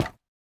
resin_brick_step2.ogg